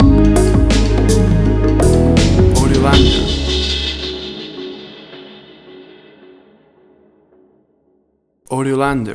Tempo (BPM): 82